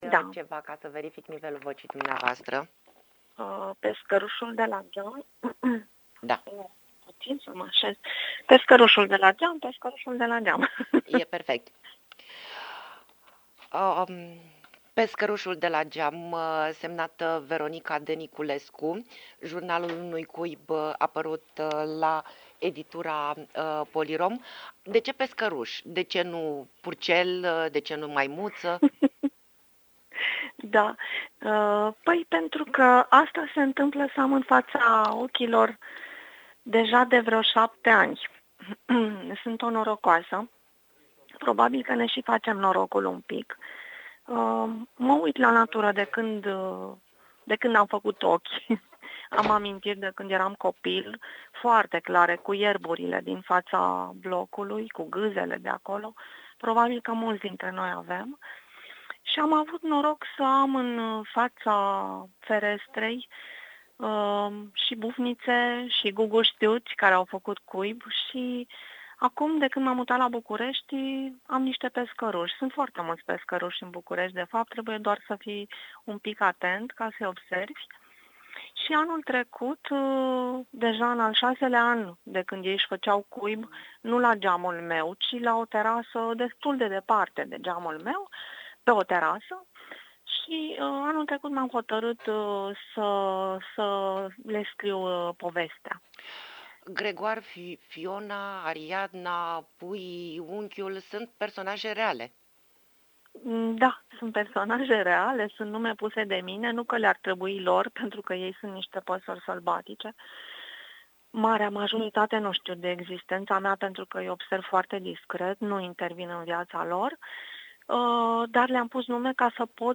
INTERVIU.